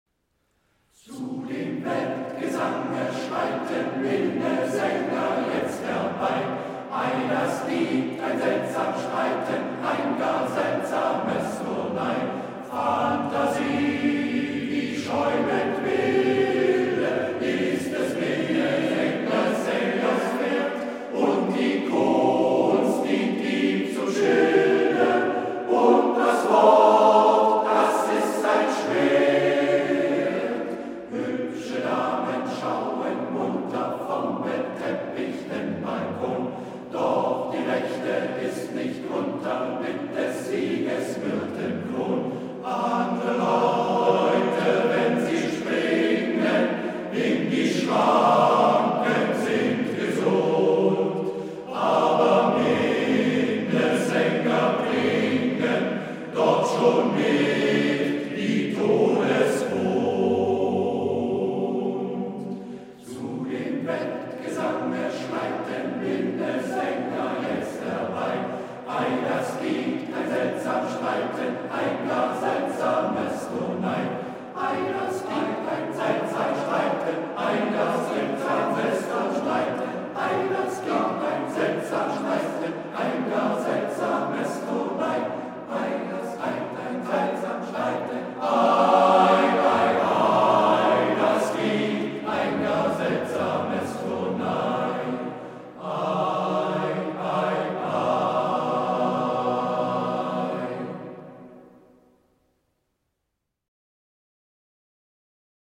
Männerchor Roßlau e.V. Seit 1834 – Tradition und Leidenschaft für den Chorgesang Startseite Geschichte Sänger Hörproben Bilder Termine Links Sponsoren Hörproben und Videos Anklicken und reinhören! Alte Weiber Auf Wiederseh'n Die Minnesänger Lied der Wolgaschlepper Lützows wilde Jagd © 2025 Männerchor Roßlau e.V. | Kontakt | Impressum | Besuchen Sie uns auf Facebook